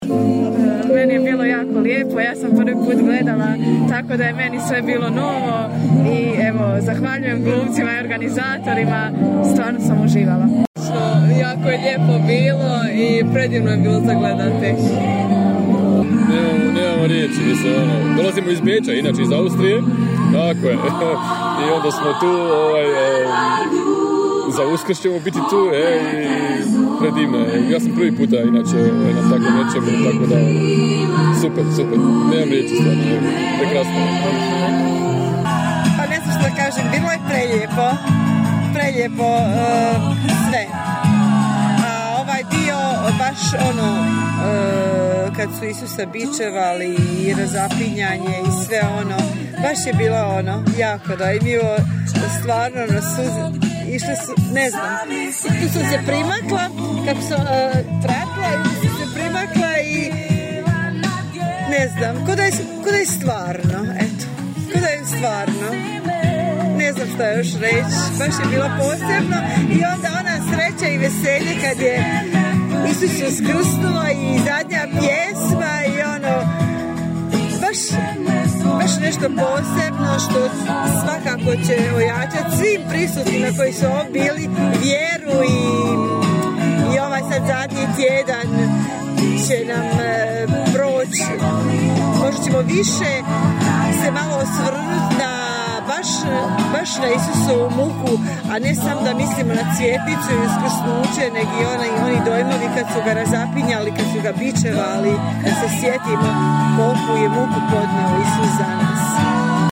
Nakon izvedbe smo razgovarali i s publikom.